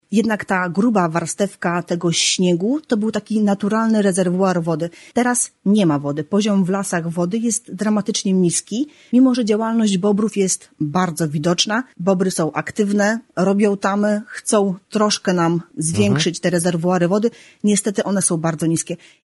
O wyjątkowo nietypowej aurze, jaką mamy tej zimy, rozmawialiśmy na antenie Radia 5